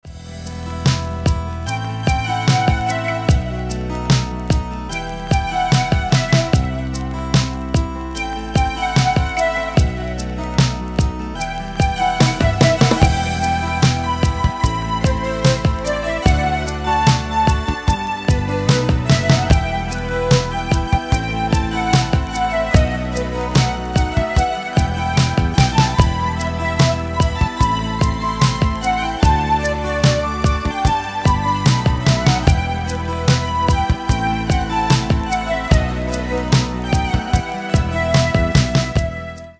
Поп Музыка
спокойные # без слов